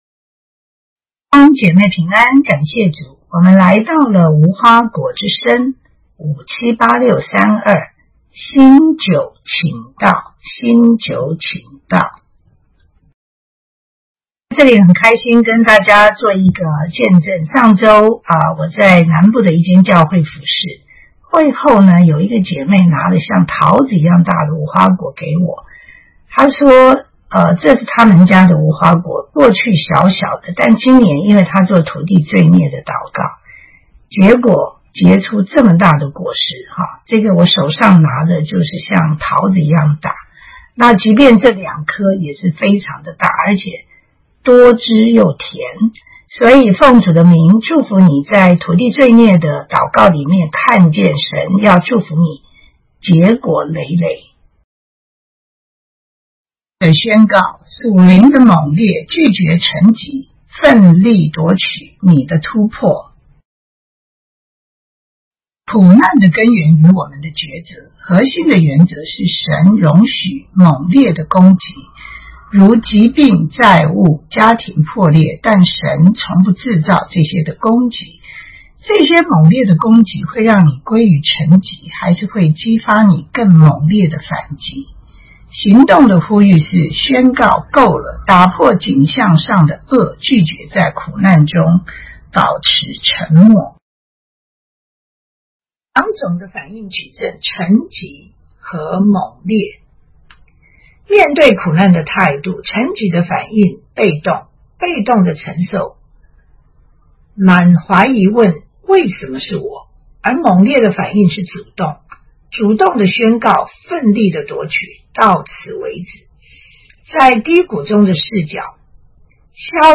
土地罪孽的祷告